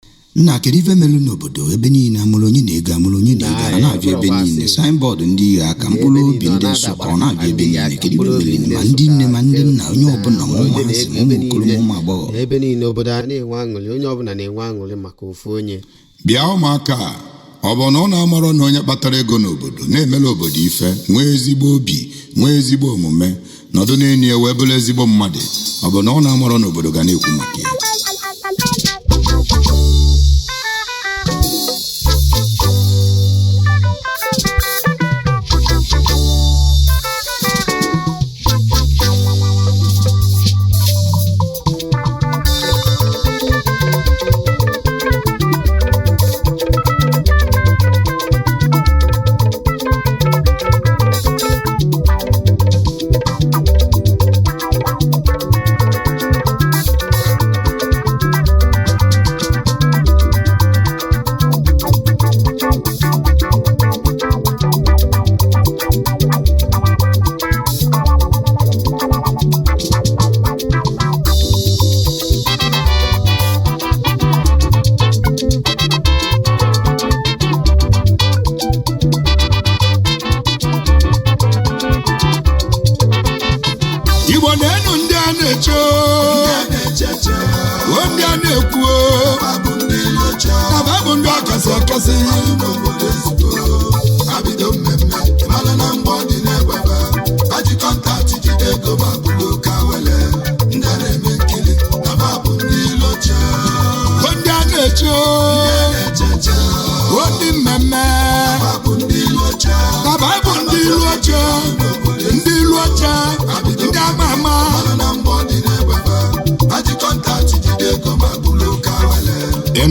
highlife track
a good highlife tune